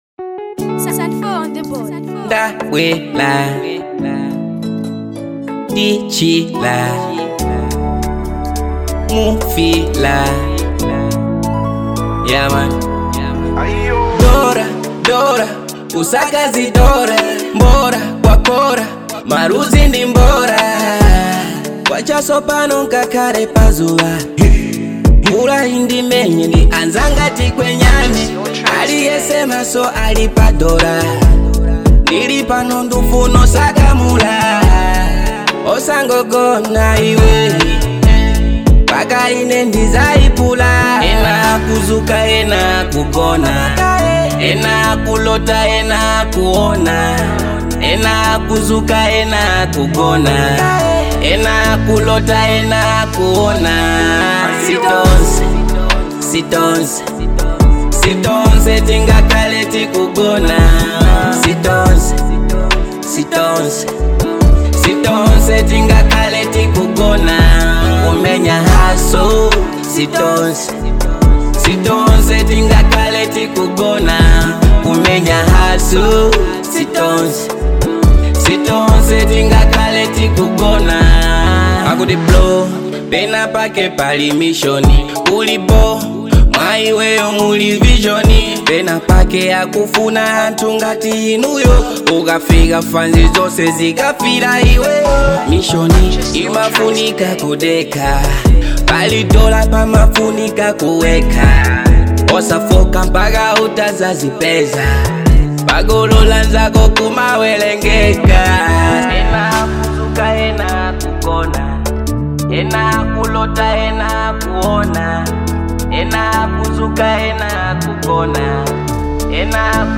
Grenre : Drill Download 👇